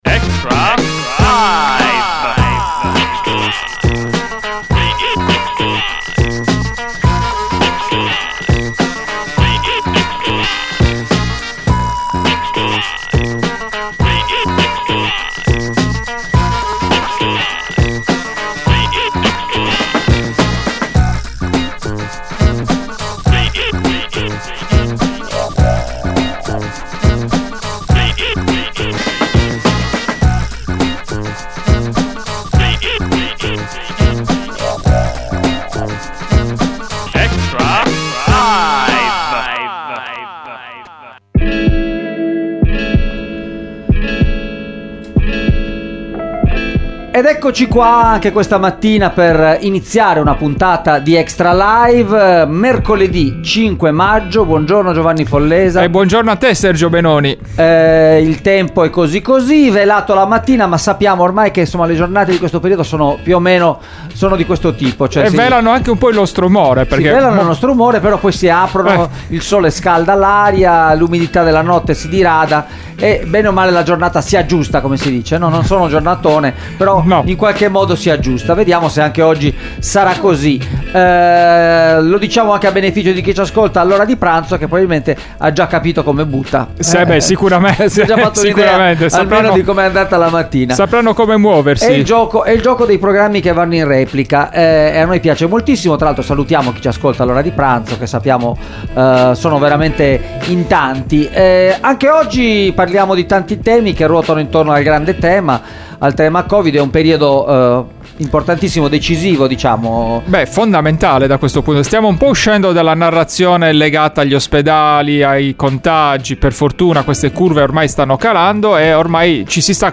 Extralive mattina: ogni giorno in diretta dalle 8 alle 9 e in replica dalle 13, il commento alle notizie di giornata dalle prime pagine dei quotidiani con approfondimenti e ospiti in studio.